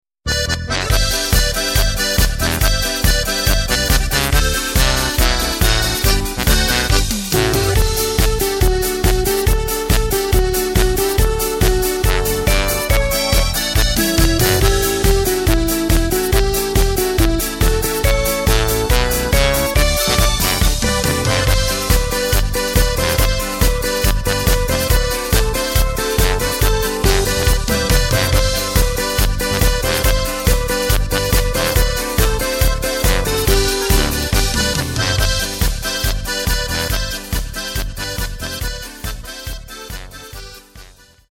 Takt:          2/4
Tempo:         140.00
Tonart:            G
Flotte Polka aus dem Jahr 2005!